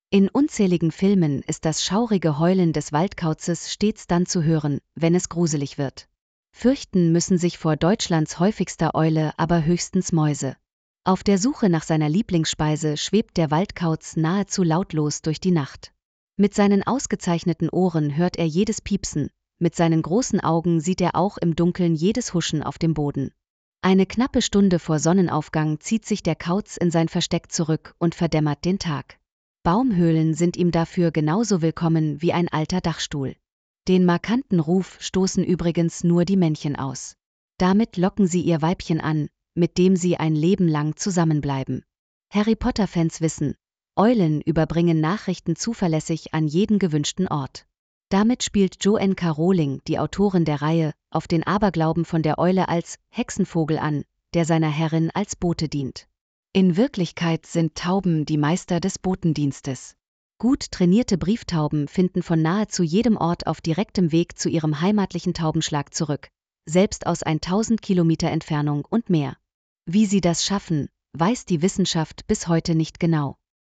Steckbrief zum Hören